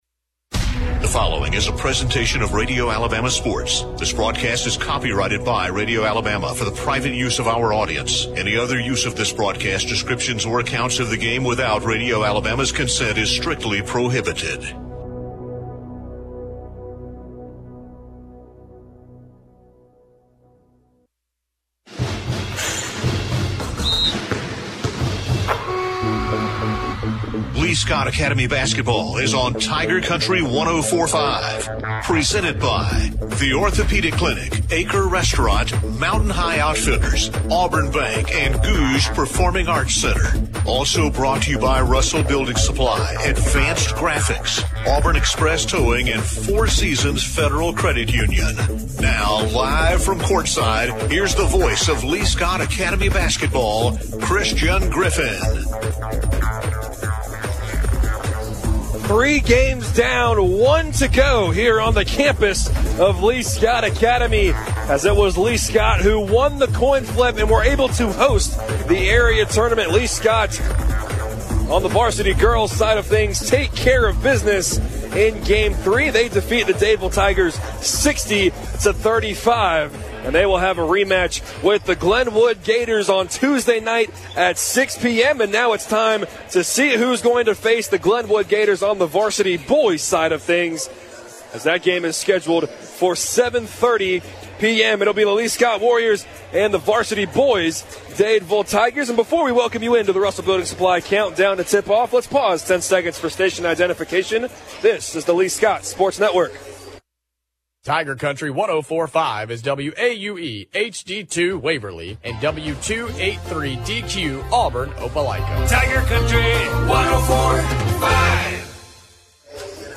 calls Lee-Scott Academy's game against the Dadeville Tigers in the first round of the Area Tournament. The Warriors won 89-61.